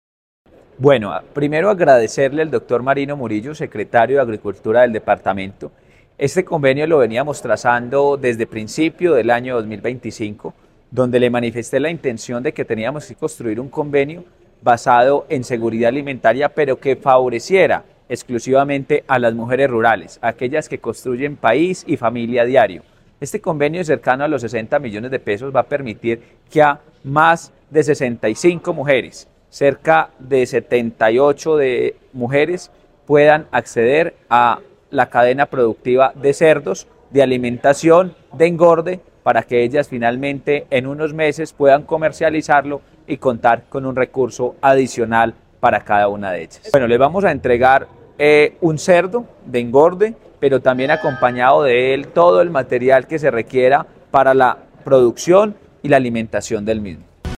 Jorge Andrés Arango, alcalde de Samaná.